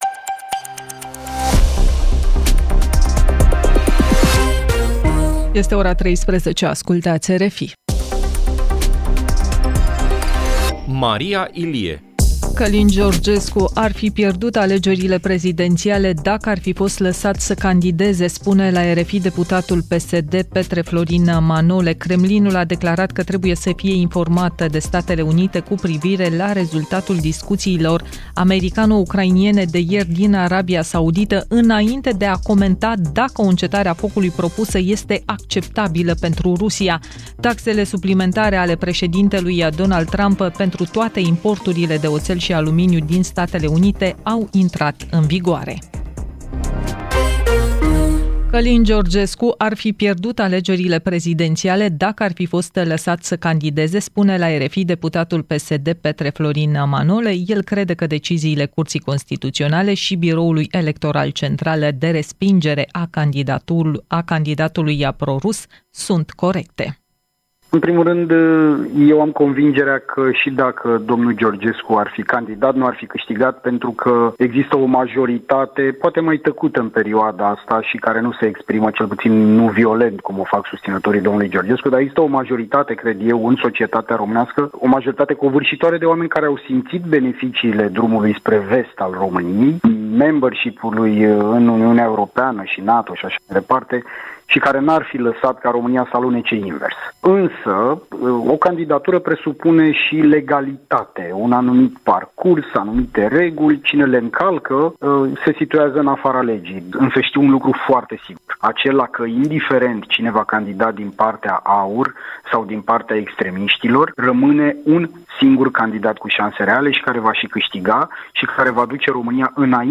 Jurnal de știri